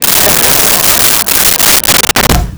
Crowd Laughing 06
Crowd Laughing 06.wav